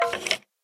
Minecraft.Client / Windows64Media / Sound / Minecraft / mob / skeleton / say2.ogg